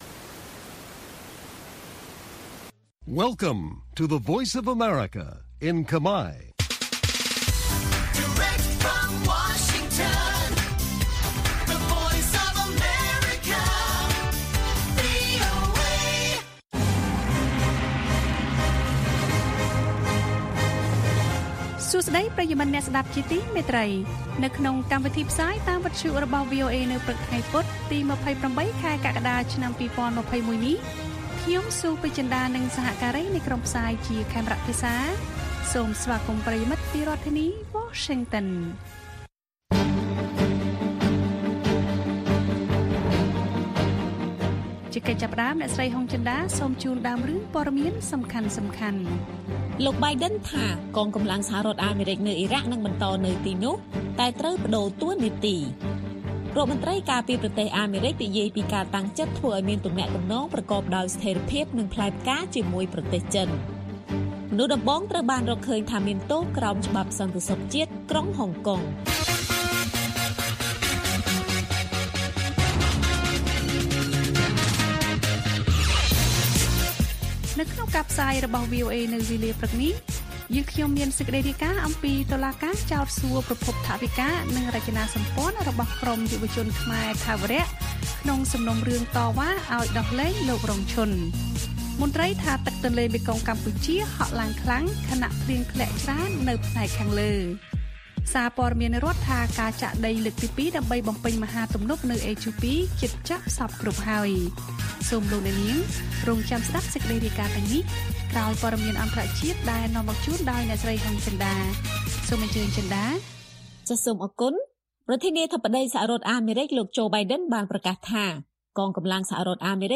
ព័ត៌មានពេលព្រឹក៖ ២៨ កក្កដា ២០២១